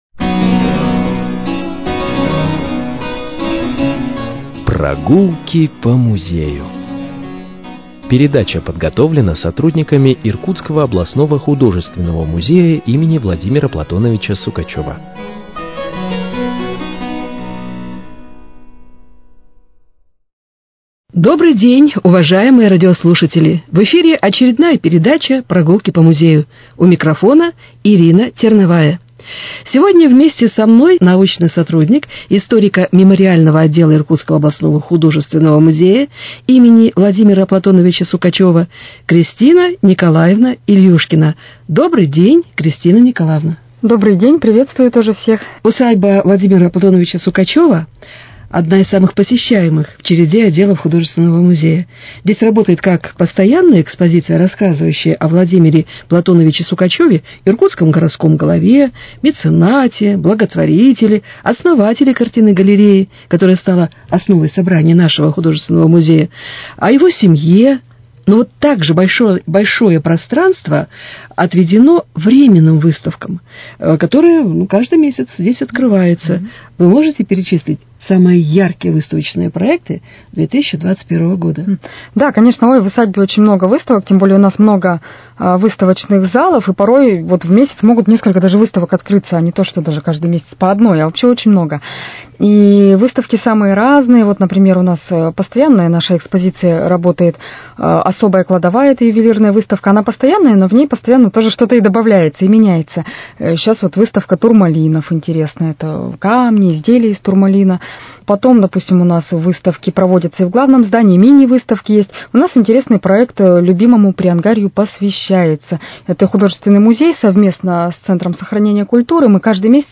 Прогулки по музею: Беседа